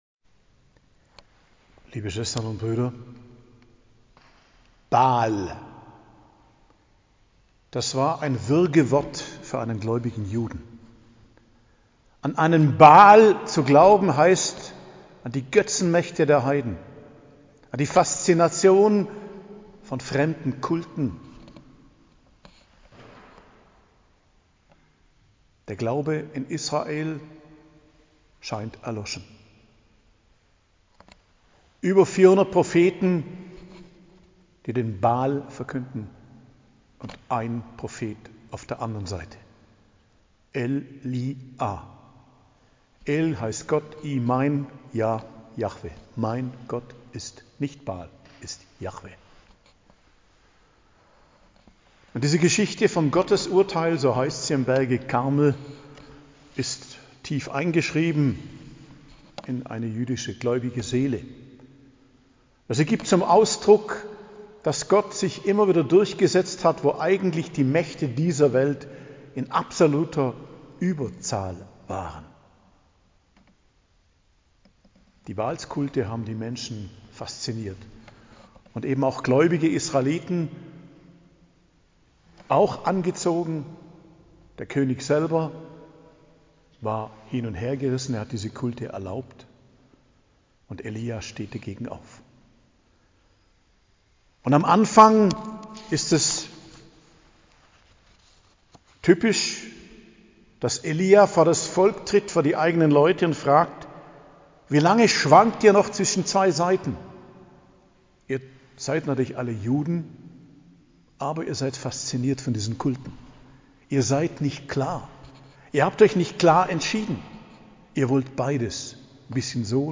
Predigt am Mittwoch der 10. Woche i.J., 8.06.2022